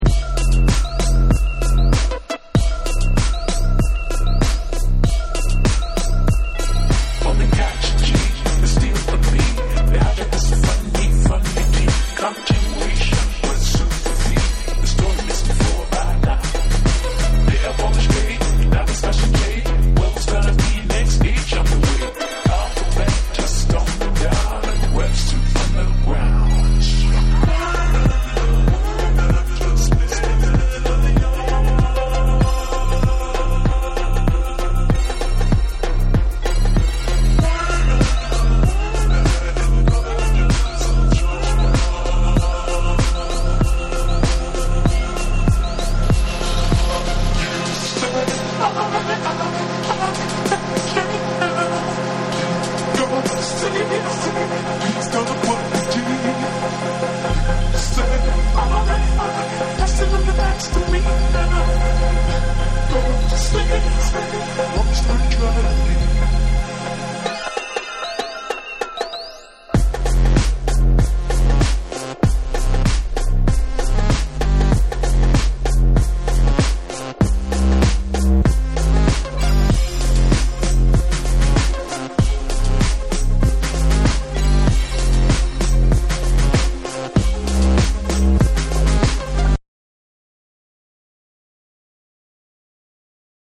さらにBPMを落としたコチラもニューウエーヴ・フレイヴァーな2も収録。